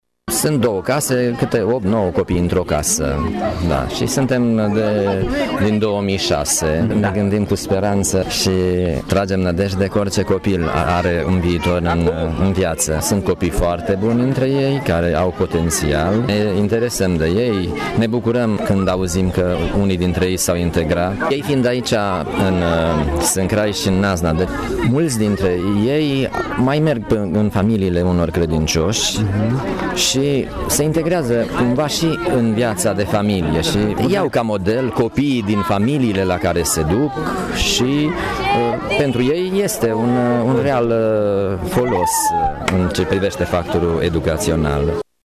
Două case de copii din județul Mureș sunt sprijinite de peste 10 ani de Filantropia Ortodoxă Tg. Mureș. Preotul